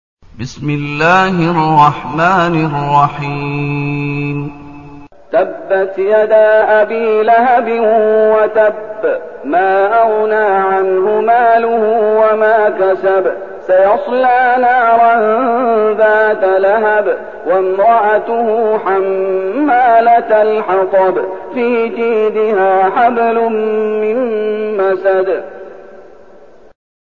المكان: المسجد النبوي الشيخ: فضيلة الشيخ محمد أيوب فضيلة الشيخ محمد أيوب المسد